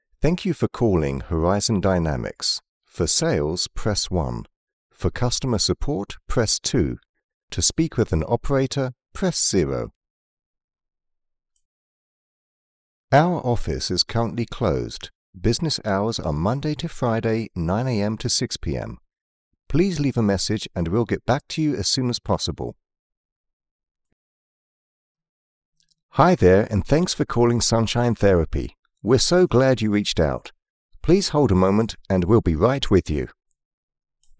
Never any Artificial Voices used, unlike other sites.
On Hold, Professional Voicemail, Phone Greetings & Interactive Voice Overs
Adult (30-50) | Older Sound (50+)